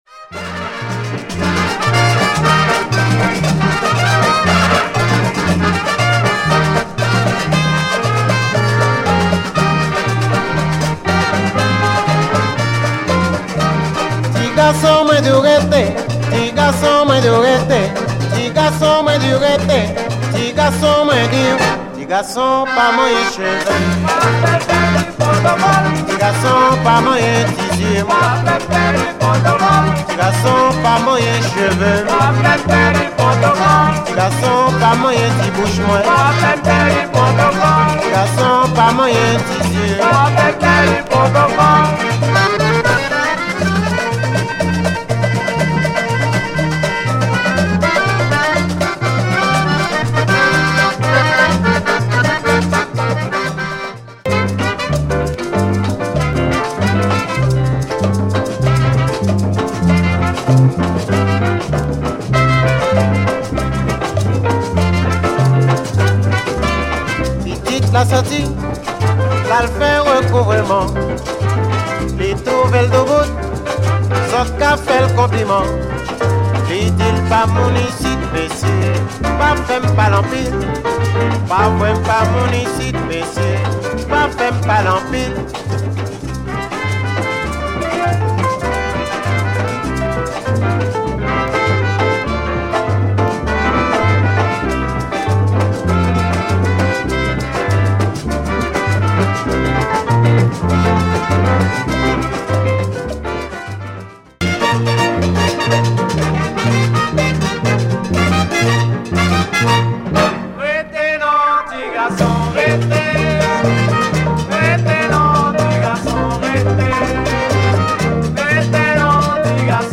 HomeWorld MusicLatin  >  Salsa / Pachanga / Mambo / …
Deep Biguines and Compas all the way, very nice LP!